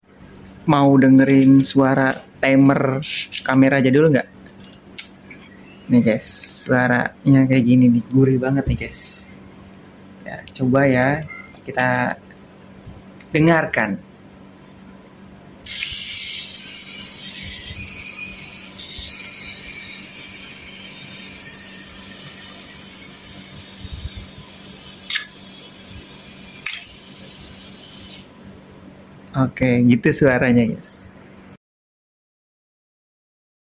Suara Gurih Timer Kamera Jadul Sound Effects Free Download